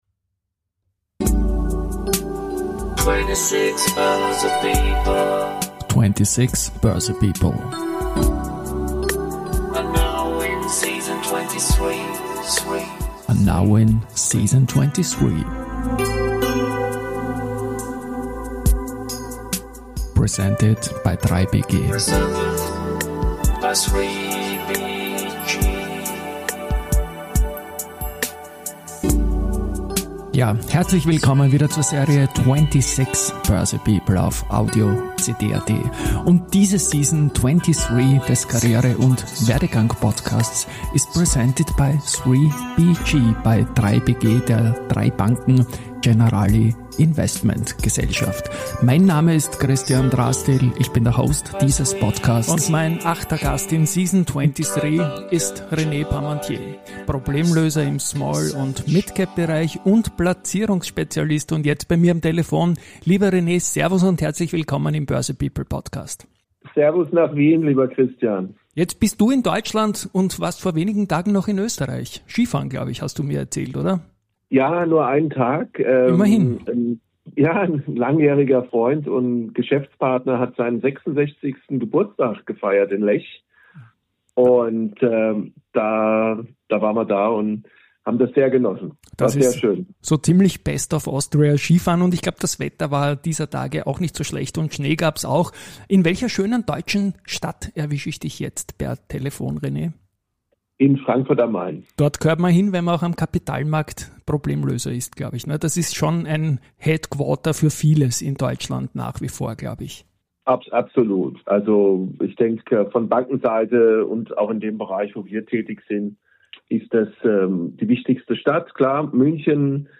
Es handelt sich dabei um typische Personality- und Werdegang-Gespräche.